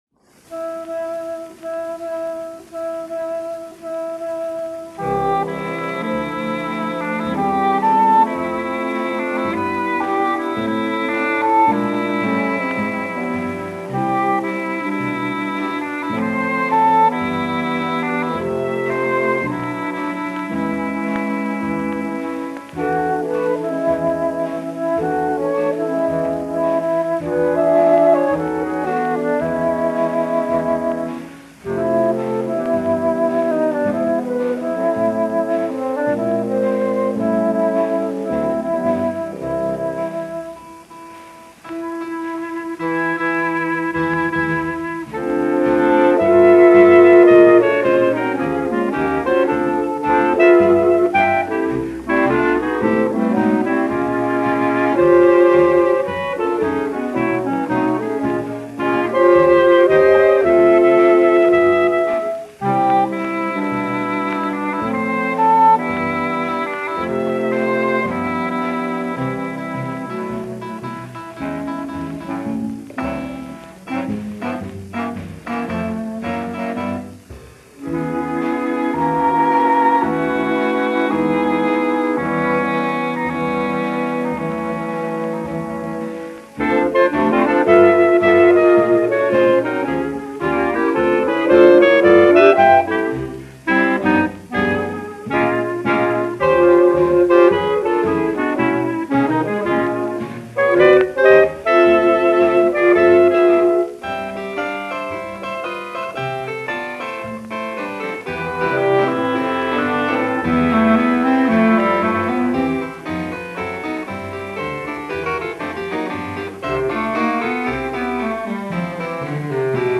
Oboist